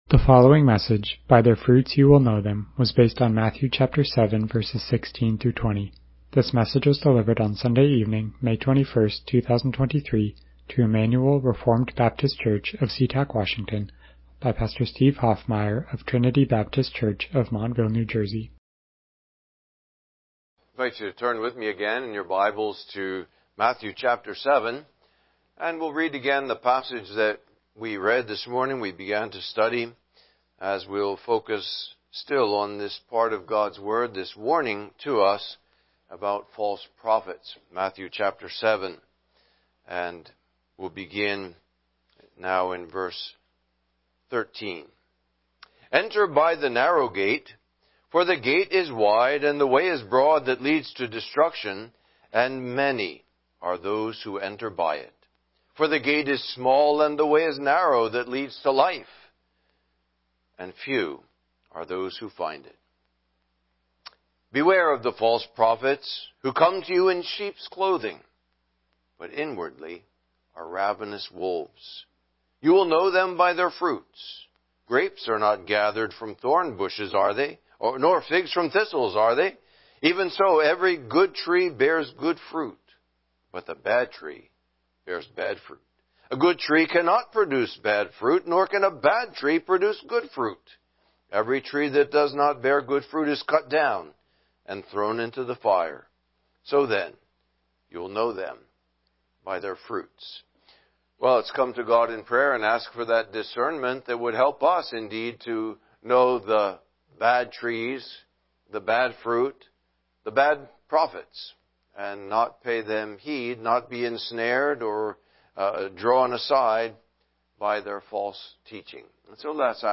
Passage: Matthew 7:16-20 Service Type: Evening Worship « Beware the False Prophets LBCF Chapter 32